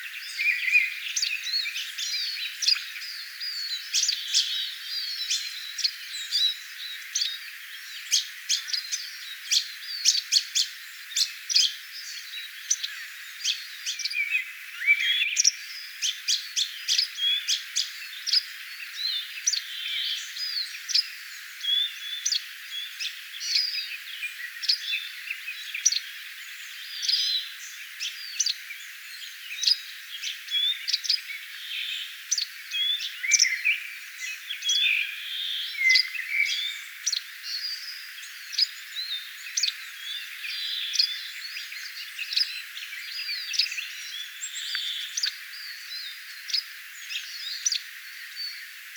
Tämä peipon poikanen äänteli erikoisesti.
erikoisesti ääntelevä peipon poikanen
erikoista_peipon_poikasen_aanta_kuin_tvink-aanen_harjoituksia.mp3